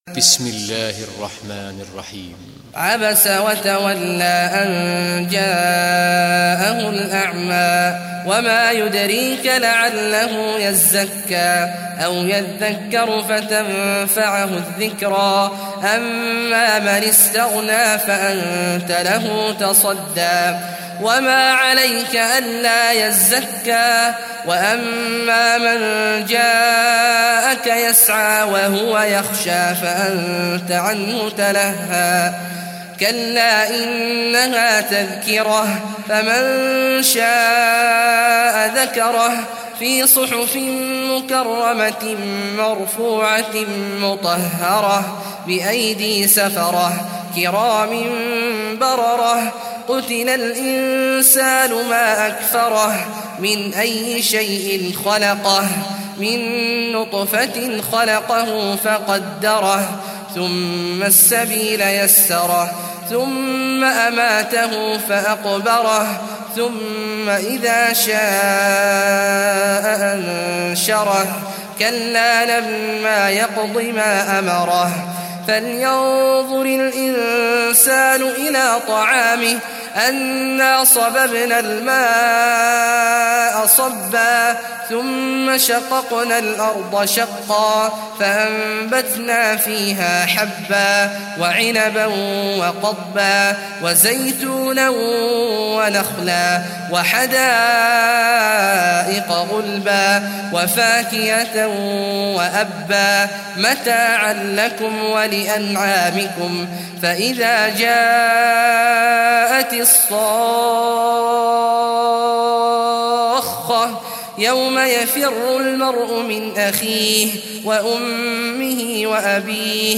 Surah Abasa Recitation by Sheikh Awad Juhany
Surah Abasa, listen or play online mp3 tilawat / recitation in Arabic in the beautiful voice of Sheikh Abdullah Awad al Juhany.